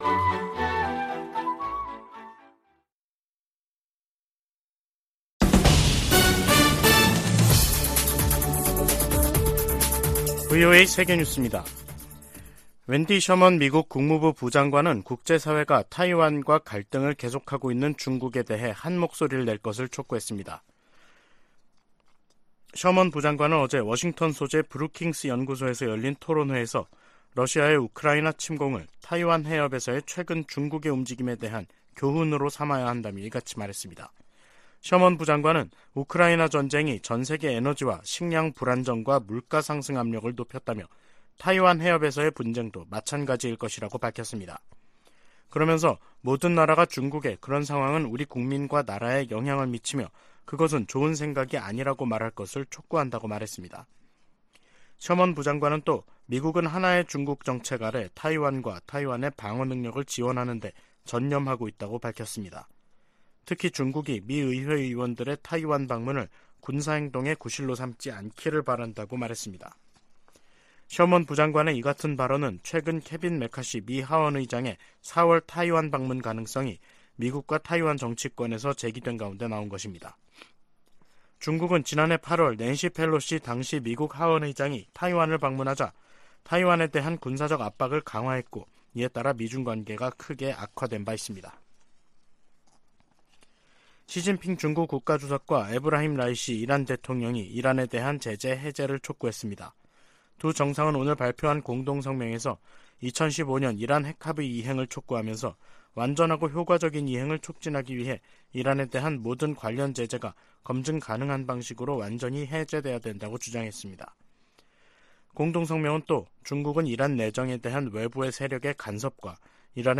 VOA 한국어 간판 뉴스 프로그램 '뉴스 투데이', 2023년 2월 16일 2부 방송입니다. 한국 정부의 국방백서가 6년만에 북한 정권과 군을 다시 적으로 명시했습니다. 미 국무부의 웬디 셔먼 부장관이 한국, 일본과의 외교차관 회담에서 북한의 도발적인 행동을 규탄하고 북한의 외교 복귀를 촉구했습니다. 미 하원에서 다시 재미 이산가족 상봉 결의안이 초당적으로 발의됐습니다.